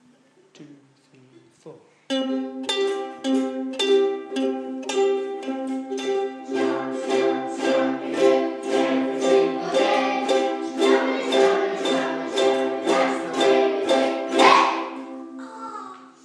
C Chord Strum with C/G Finger plucking.
Strum with finger pluck!